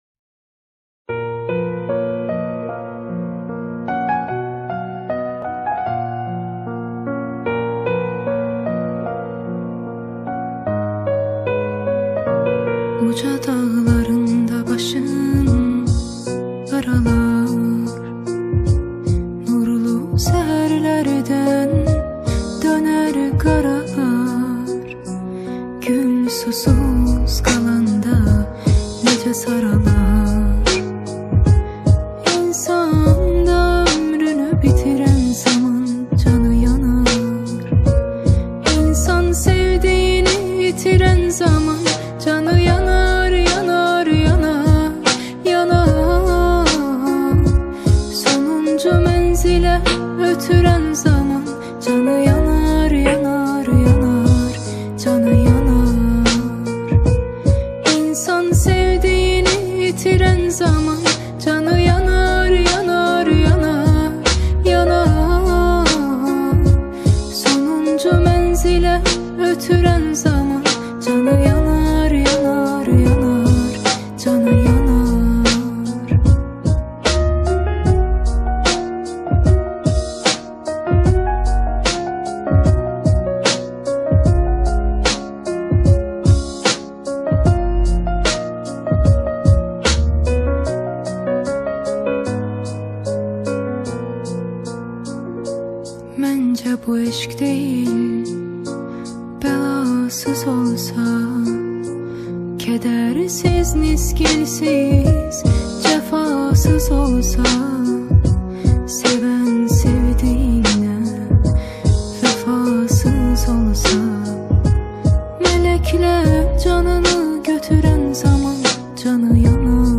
ریمیکس پیانو بیت دار